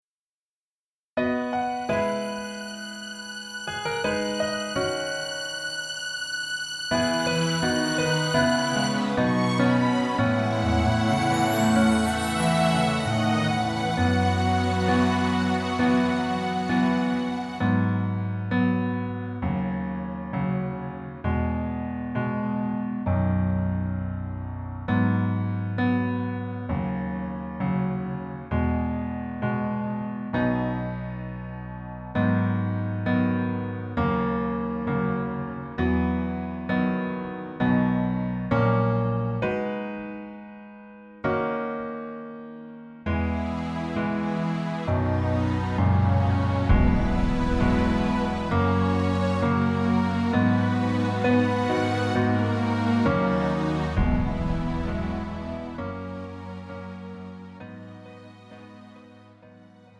음정 원키 3:57
장르 가요 구분 Pro MR